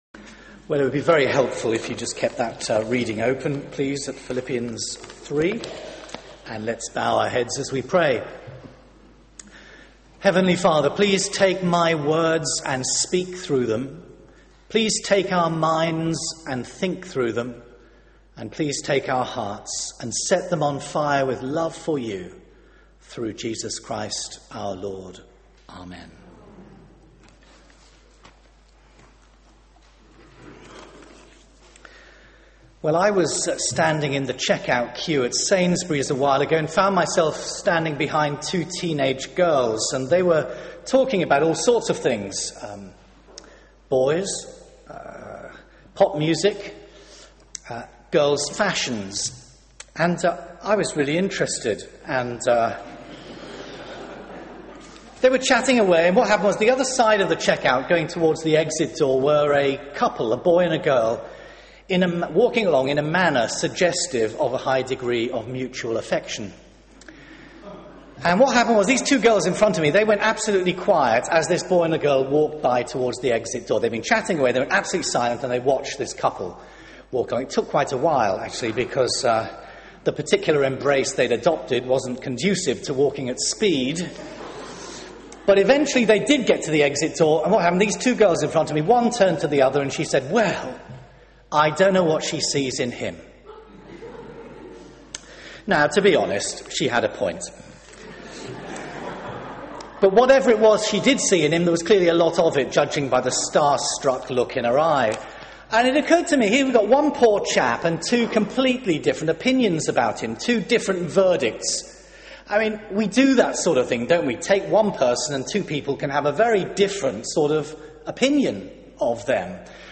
Media for 9:15am Service on Sun 26th Jun 2011 09:15 Speaker
Series: Partners in Christ Theme: True confidence Sermon